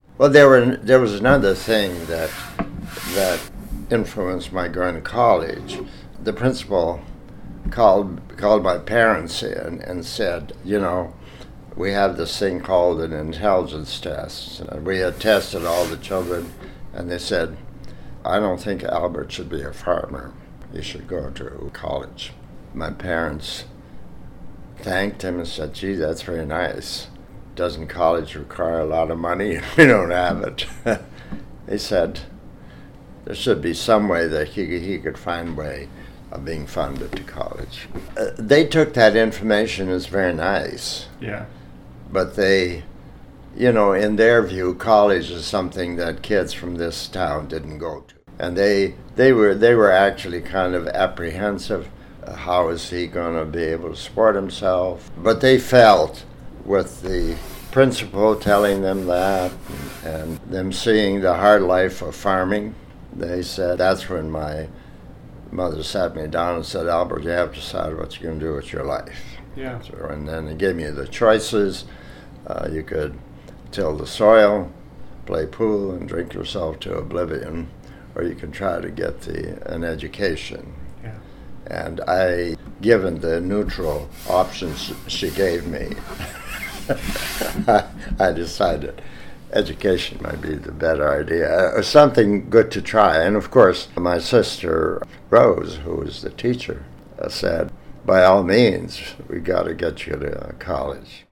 And so, it is with great pleasure that I share with you some tidbits, excerpts, and reflections from my conversations with Dr. Albert Bandura, the David Jordan Professor Emeritus of Social Science in Psychology at Stanford University.
Here Dr.  Bandura describes at least one reason for that decision: